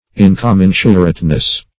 In`com*men"su*rate*ness, n.